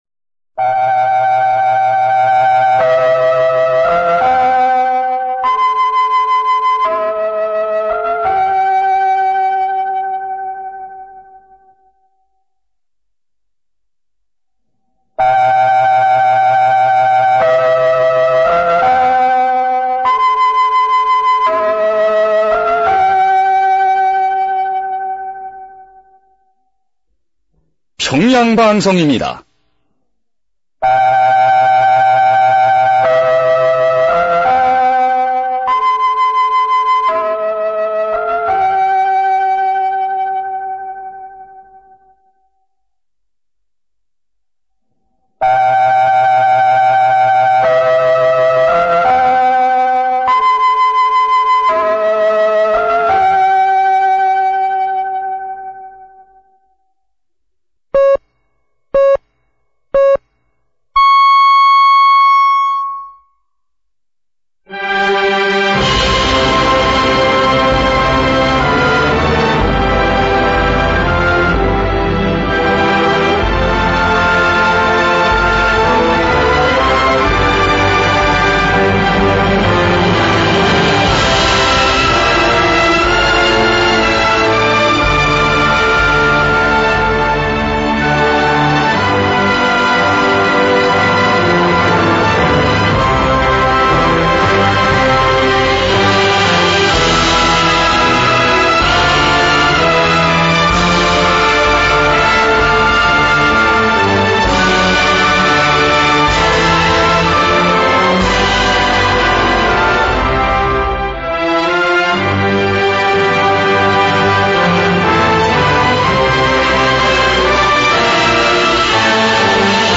[A-DX] DigiDX#10 + #11 UNID IS
Klingt doch ziemlich nach Nordkorea?